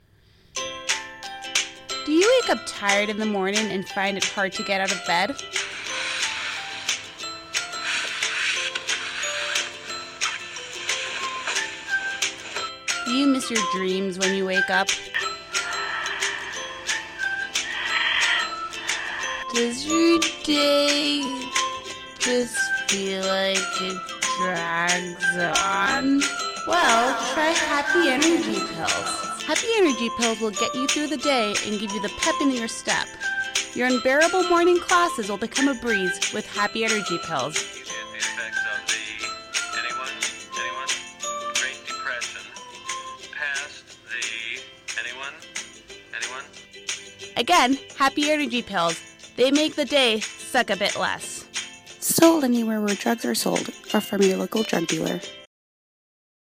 Happy Energy Pill Commercial
happy-energy-pills-commercial.mp3